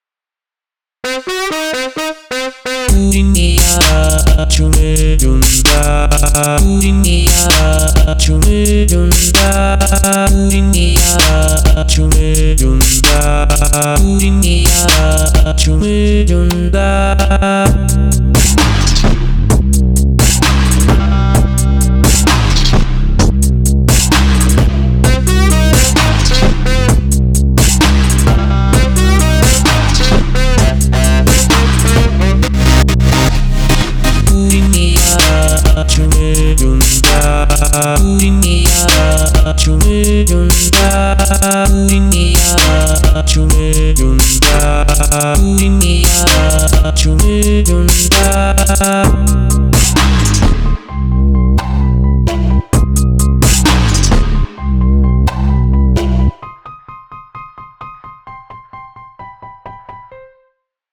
ELECTRONICA – EDM